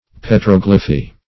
Search Result for " petroglyphy" : The Collaborative International Dictionary of English v.0.48: Petroglyphy \Pe*trog"ly*phy\, n. [Petro + Gr. gly`fein to carve.] The art or operation of carving figures or inscriptions on rock or stone.